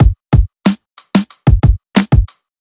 FK092BEAT2-R.wav